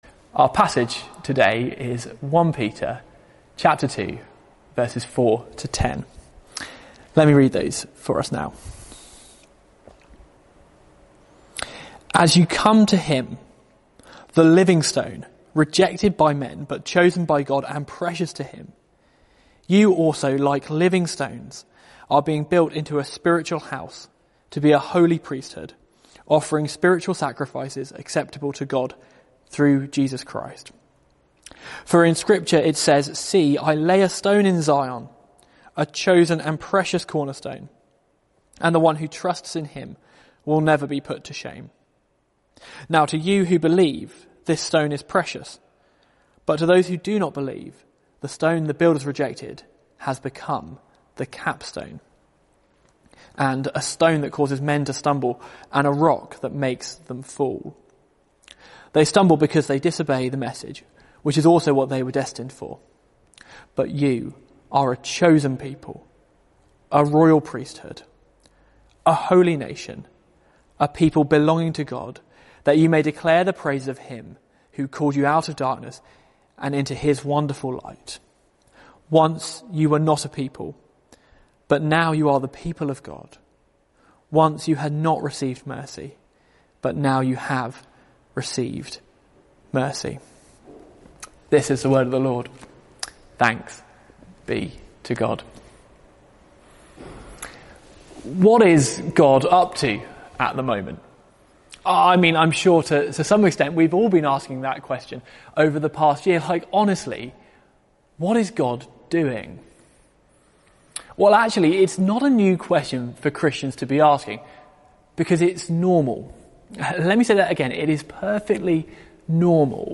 Media for 6:30pm Service on Sun 24th Jan 2021 18:30 Speaker
Sermon (Audio)